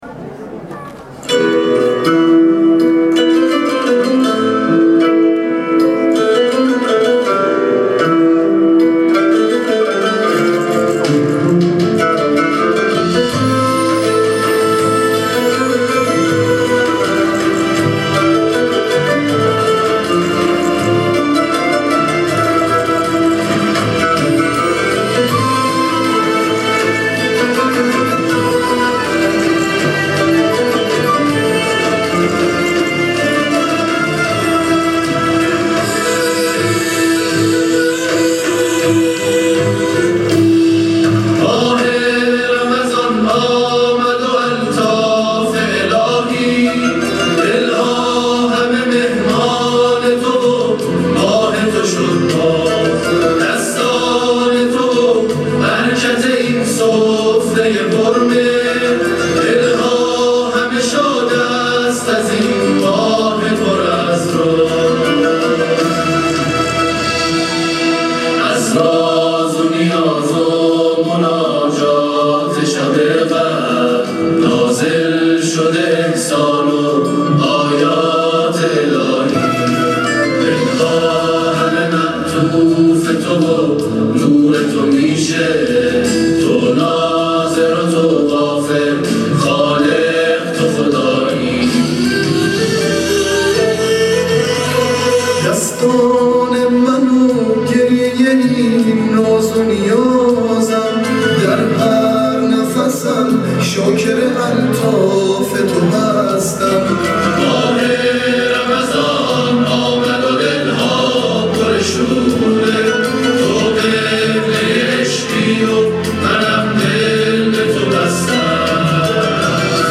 گروه مسابقات: آئین اختتامیه چهارمین دوره مسابقات قرآن سازمان بازنشستگی شهرداری تهران در روز ولادت امام حسن مجتبی(ع) در مرکز همایش‌های برج میلاد برگزار شد.
هم‌آوایی گروه تواشیح ال طاها در وصف امام حسن مجتبی(ع)